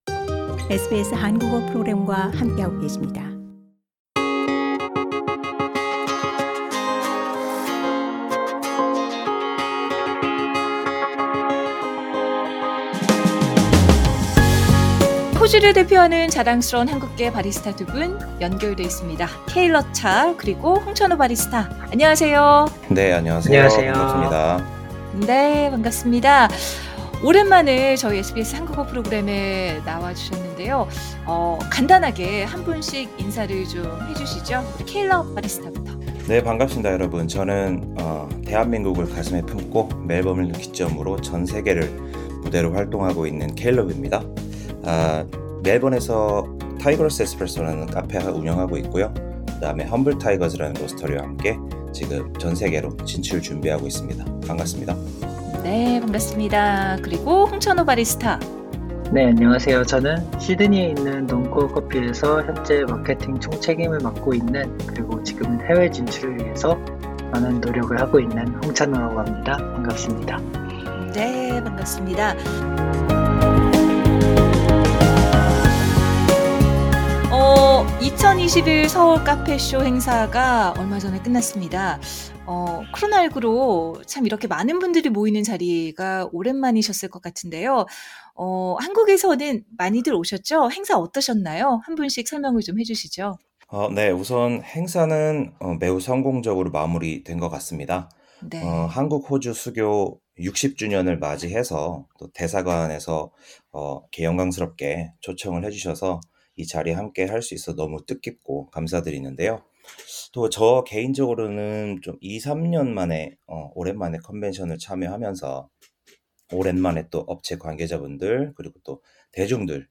이날 행사에서는 시드니와 멜버른 커피를 비교 시음하는 세션을 진행했습니다. 이 두 분 지난달 15일 서울카페쇼가 끝나자마자 연결해 이야기 나눠봤습니다.